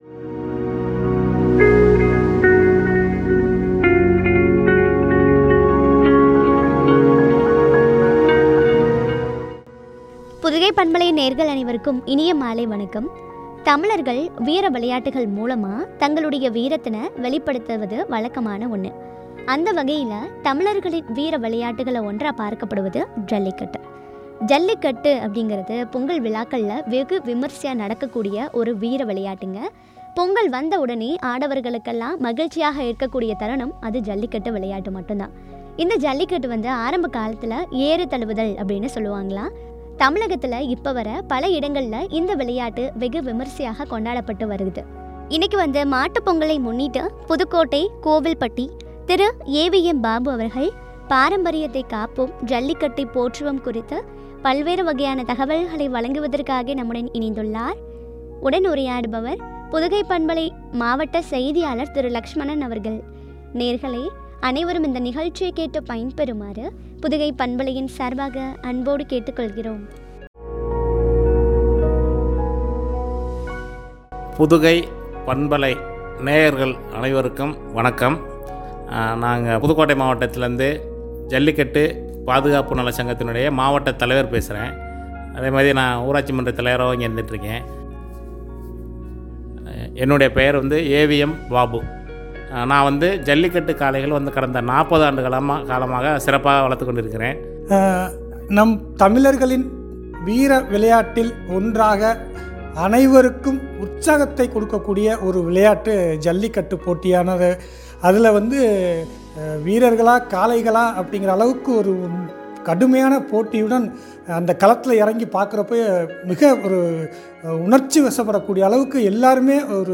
ஜல்லிக்கட்டை போற்றுவோம்” குறித்து வழங்கிய உரையாடல்.